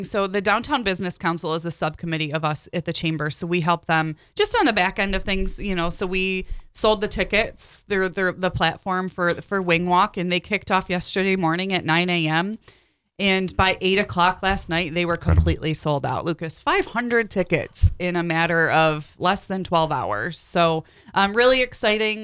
provided us with this update on Thursday’s Chamber Talk program on WFLR.